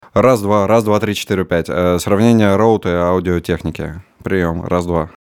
Просто думаю есть ли смысл в линейке 40хх, при условии что комната не заглушена и преамп встроенный в карту (komplete audio 6)? 4047 считается лучше чем 4040? Вот пример моего голоса записанного в 2020 (у него жесткий звук, и нравится нижняя середина). 40хх это другие микрофоны? или тот же звук по жесткости и окрасу, но дороже/детальнее?
Голос реально проблемный)) Вложения at2020.mp3 at2020.mp3 205,2 KB · Просмотры: 261